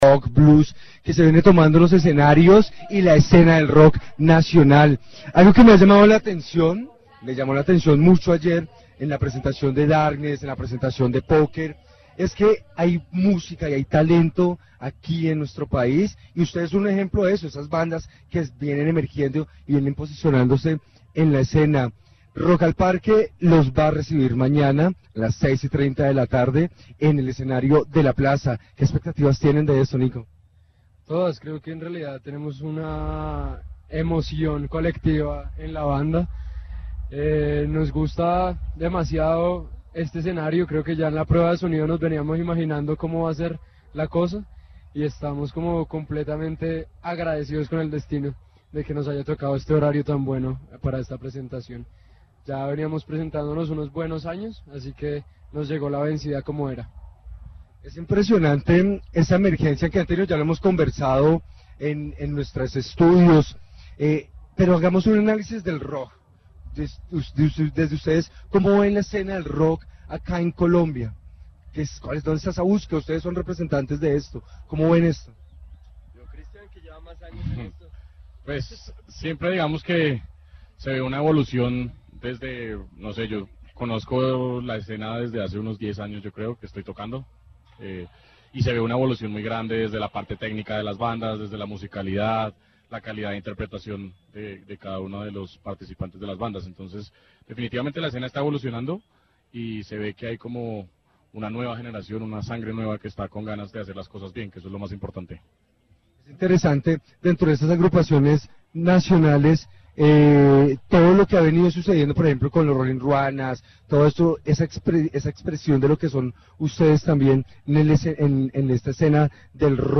Interview conducted during Rock al Parque 2017 with the band Los Mackenzie, who share their expectations for their performance on the Plaza stage, their perspective on the evolution of the rock scene in Colombia, and the band's plans after the festival, including the release of a new EP recorded in New York and upcoming national and international tours. The musicians emphasize the importance of delivering a unique show each time and describe their style as metaphorical and open to multiple interpretations.